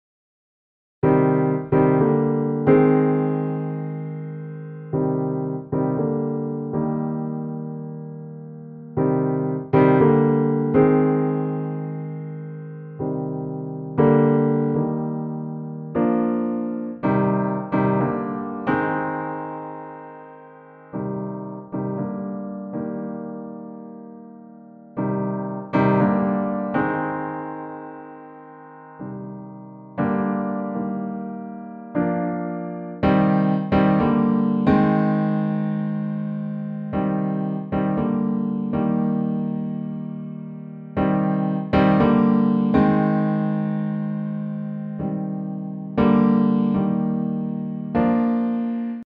На примере во вложении вначале звучит Kontakt The Gentleman , после него NY Grand S274 NY Grand S274 в 10 раз более тяжелый ~30 GB, чем Kontakt The Gentleman.